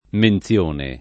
vai all'elenco alfabetico delle voci ingrandisci il carattere 100% rimpicciolisci il carattere stampa invia tramite posta elettronica codividi su Facebook menzione [ men ZL1 ne ] s. f. — non mensione — sim. il cogn.